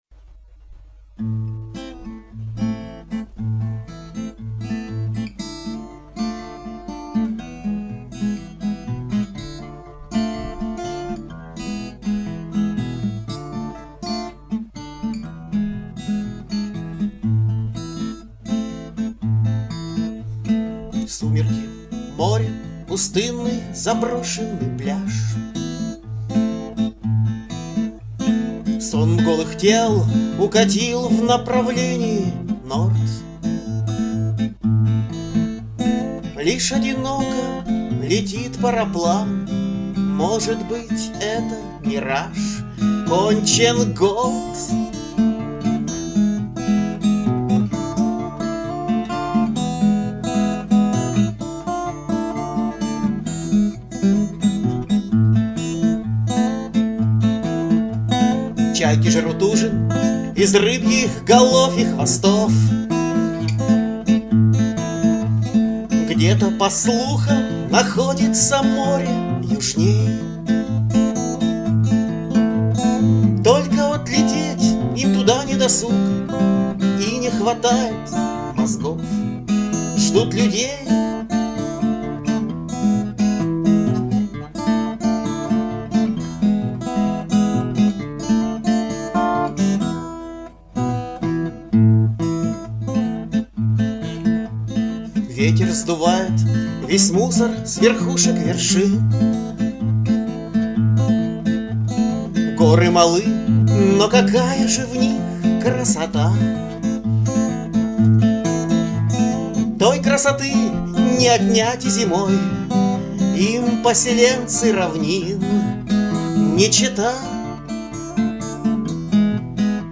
Бардрок (4123)